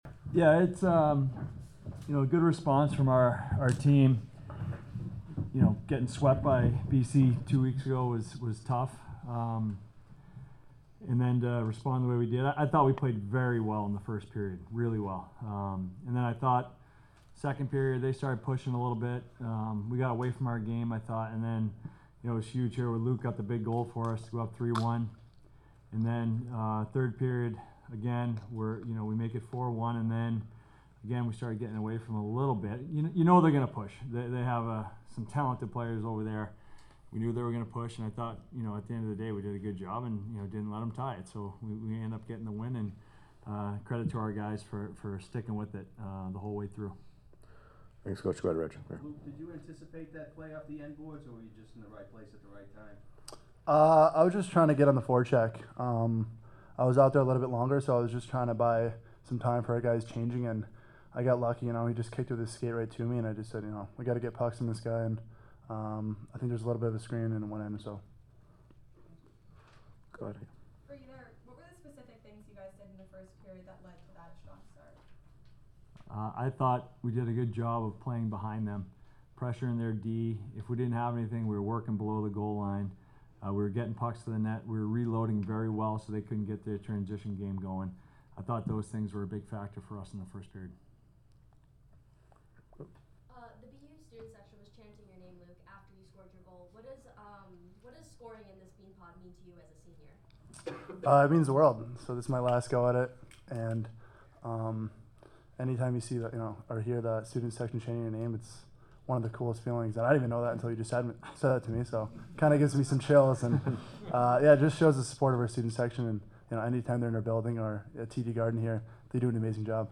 Men's Ice Hockey / Beanpot Semifinal Postgame Interview